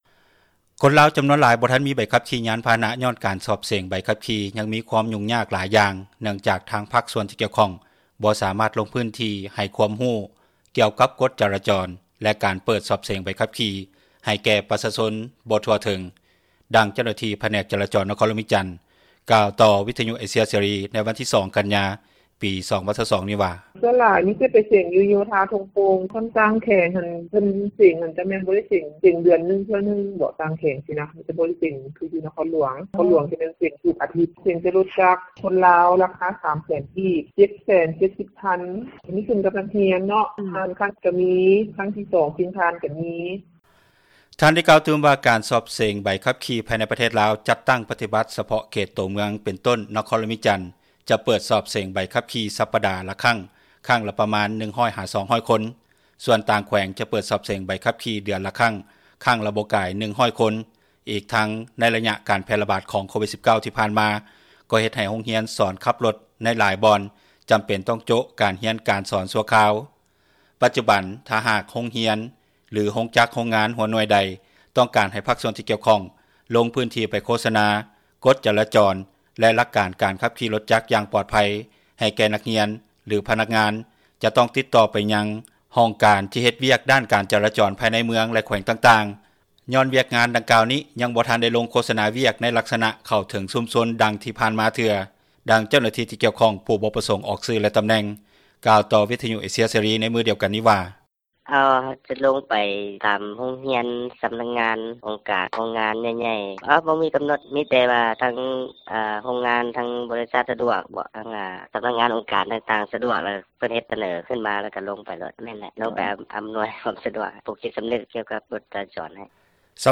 ດັ່ງພນັກງານ ຂັບຣົຖໂດຍສານ ຢູ່ນະຄອນຫຼວງວຽງຈັນ ກ່າວວ່າ: